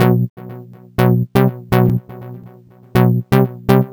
Rave-O-Lution B 122.wav